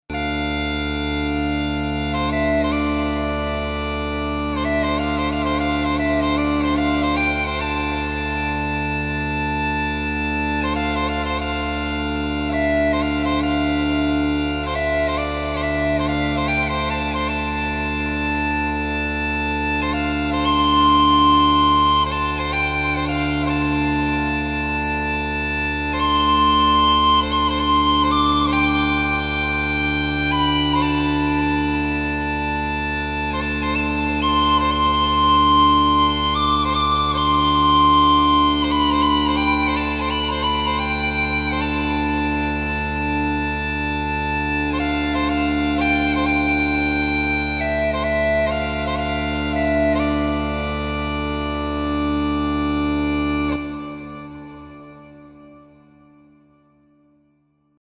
Description: Traditional bagpipe melody.
Instrumental music.
Genres: Background Music
Tempo: slow